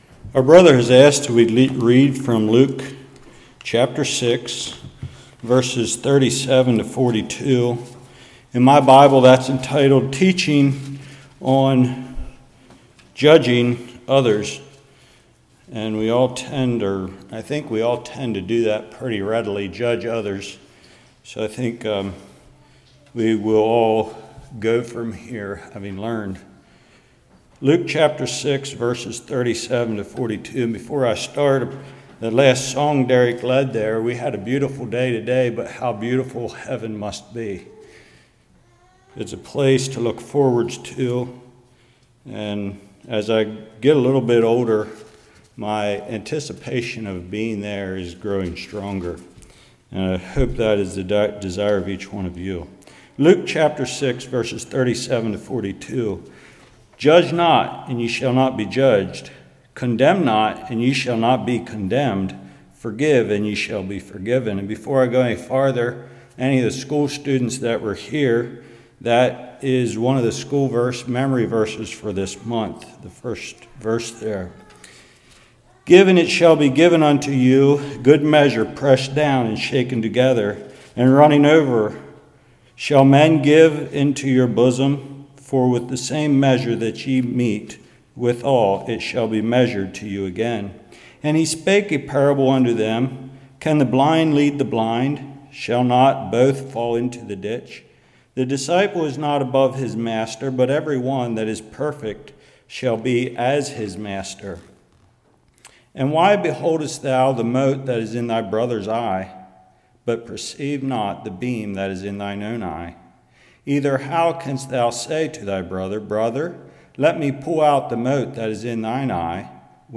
Luke 6:37-42 Service Type: Evening v37 discerning and judging others